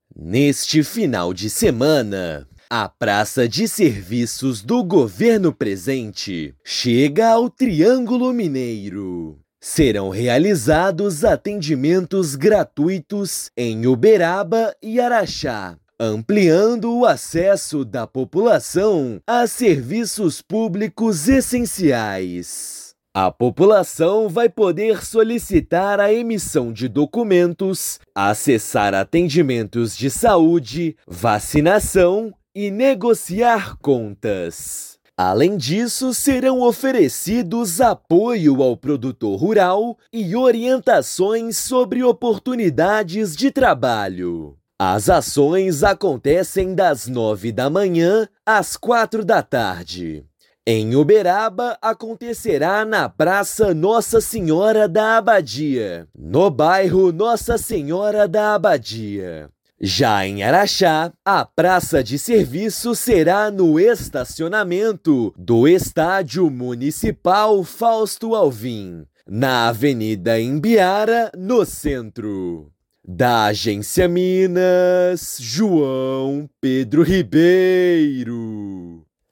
Durante sábado (25/4) e domingo (26/4), emissão de identidade, serviços de saúde, vacinação, castração de animais e negociação de contas estarão disponíveis nas duas cidades. Ouça matéria de rádio.